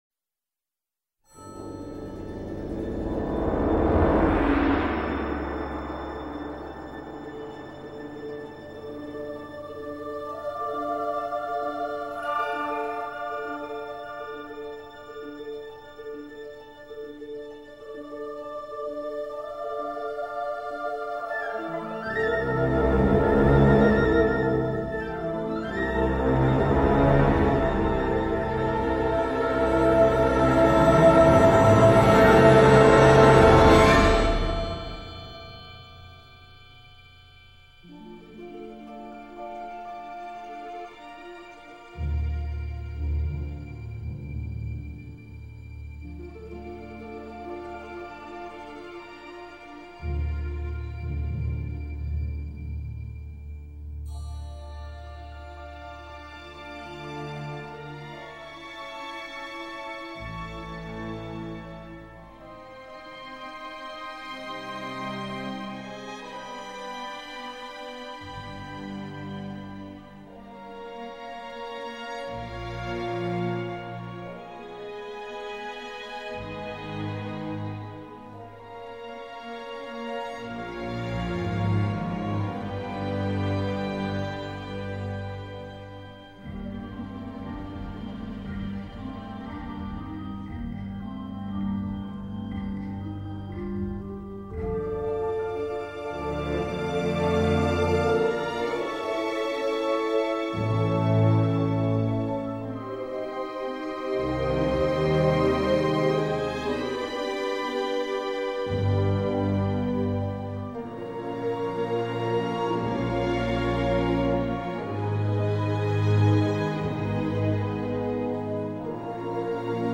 La bête est sexy, flippante, fièrement orchestrée.